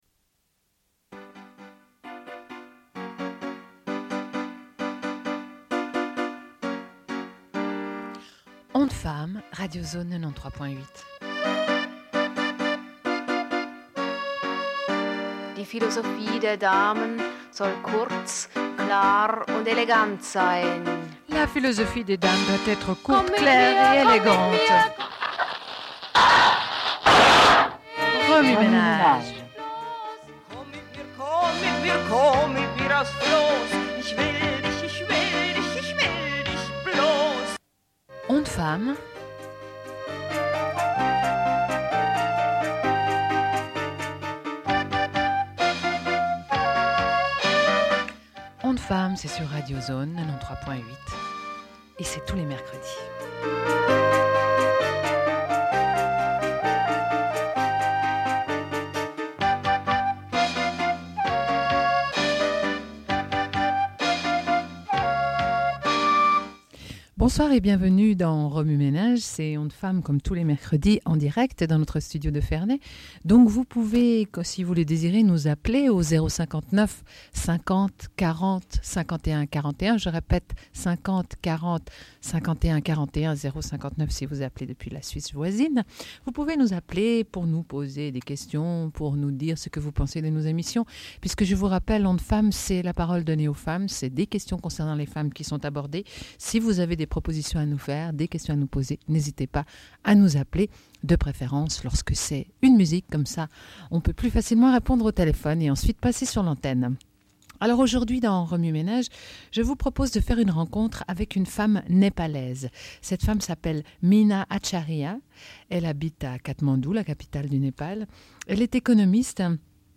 Une cassette audio, face A
Radio